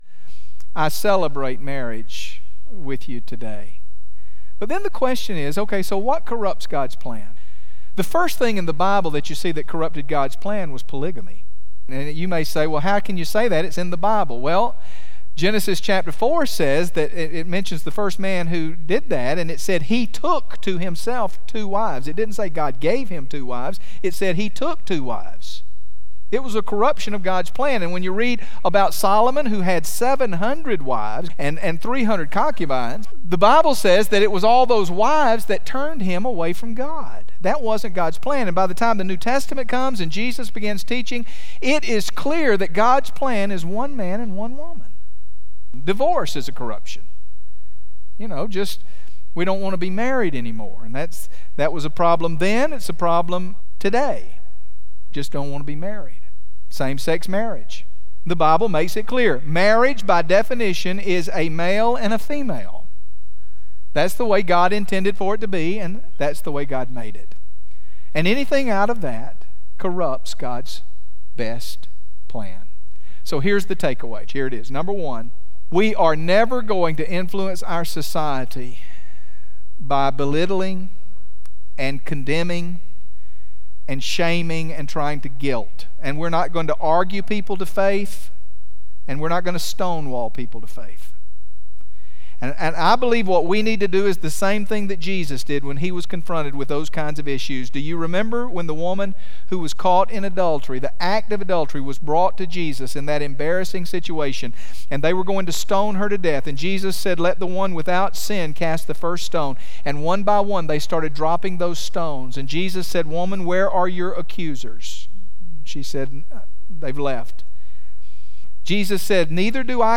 Just prior to saying what is carried in the clip below, he celebrated marriage by recognizing couples in the congregation who had been married for twenty or more years, then couples who would have stood had their spouses not already gone to be with the Lord. What he said next is an example for every believer about how to uphold marriage.